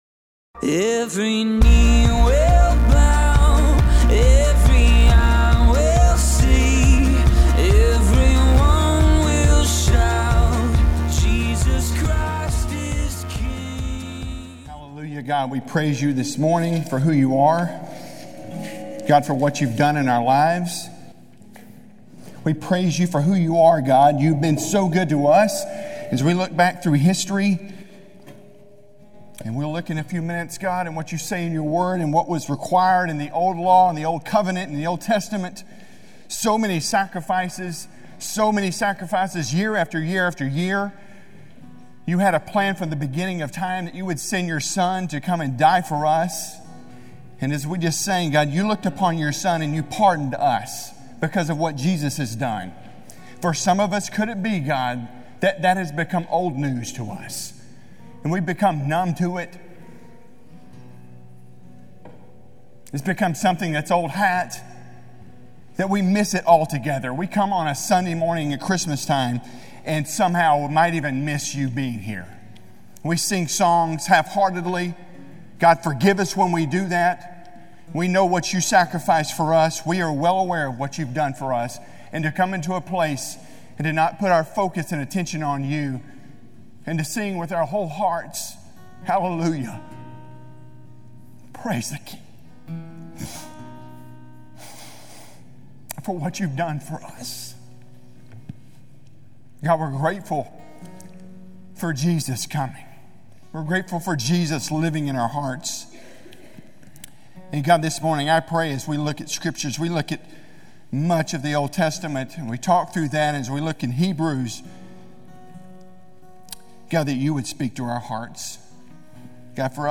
A message from the series "Advent."